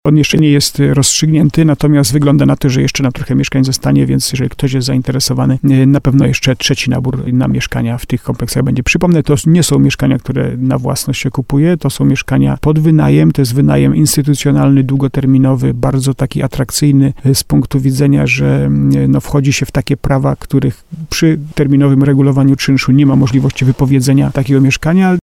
Przy terminowym regulowaniu czynszu, nie ma możliwości wypowiedzenia lokatorowi mieszkania – mówi Jacek Lelek, burmistrz Starego Sącza.